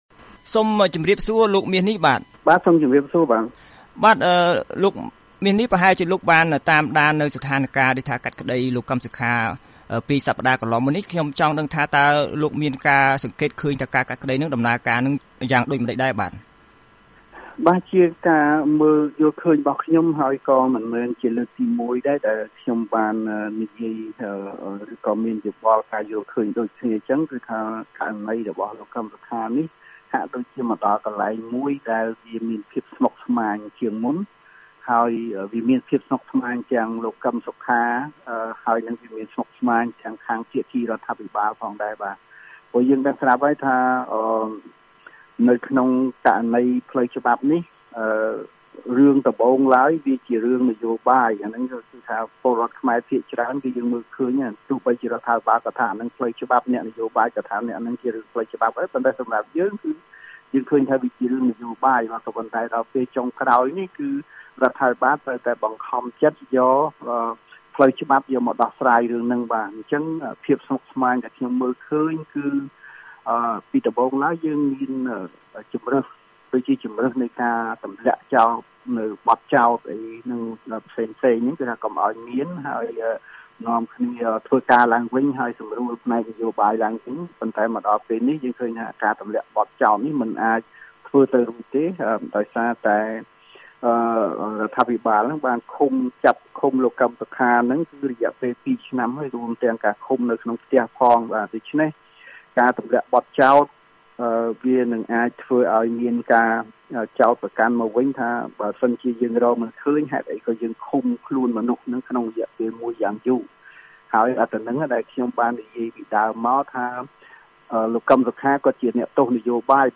បទសម្ភាសន៍ VOA៖ សវនាការលោកកឹម សុខា កាន់តែស្មុគស្មាញ ខណៈមានការចោទប្រកាន់លើប្រទេសផ្សេងទៀត